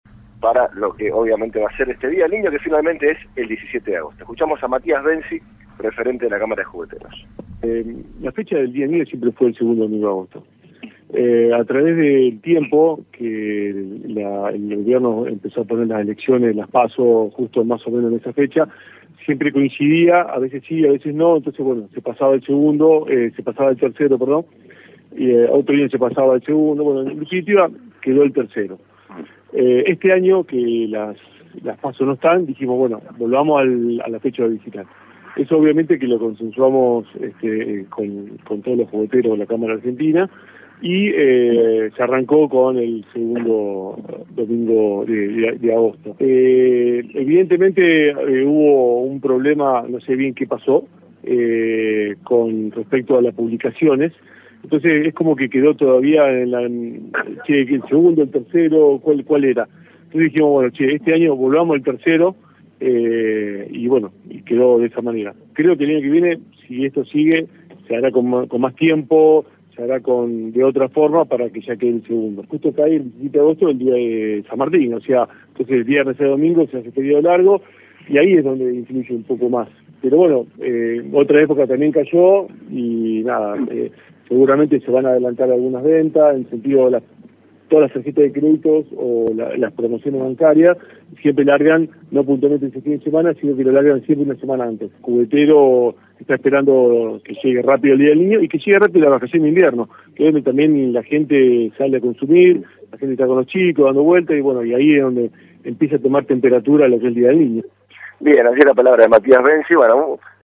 habló con el móvil de LT3 y explicó los motivos por los cuales se prestó a confusión la fecha de celebración del día del niño